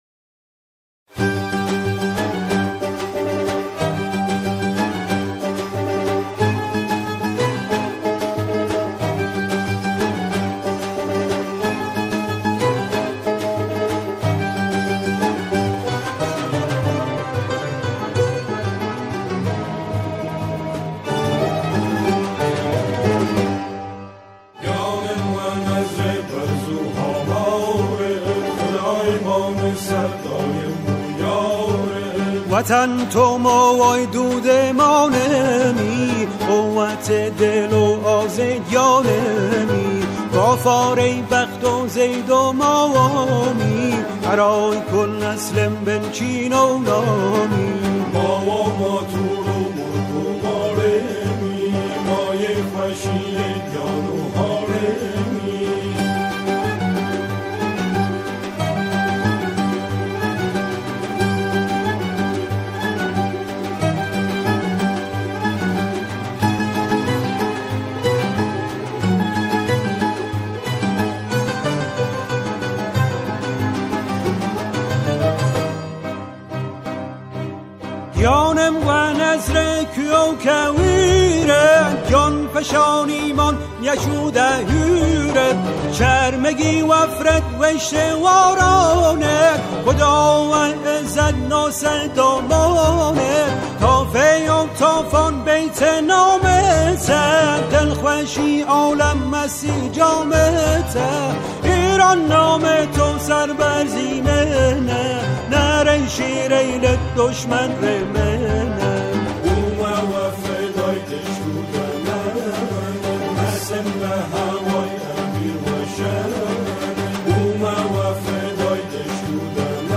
گروهی از همخوانان هستند